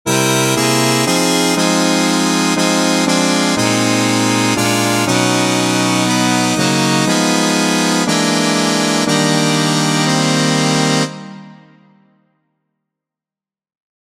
Key written in: E♭ Major
How many parts: 4
Type: Barbershop
All Parts mix: